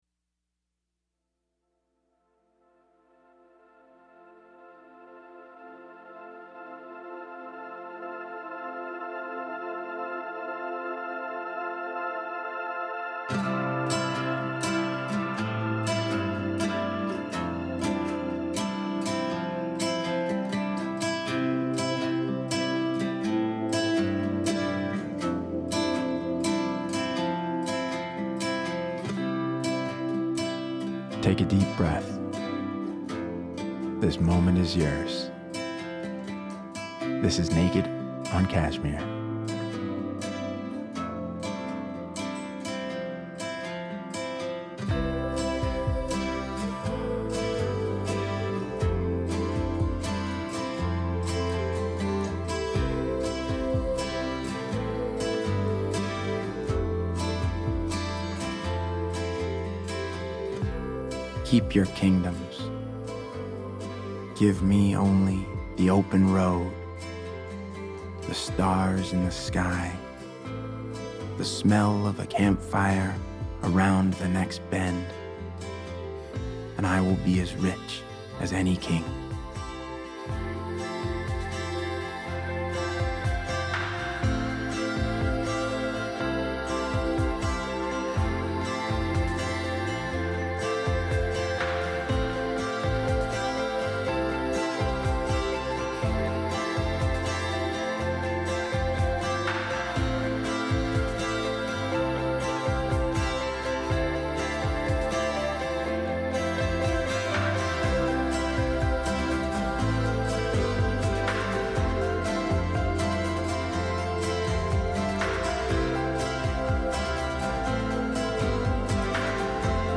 Atticus presents a daily poetry reading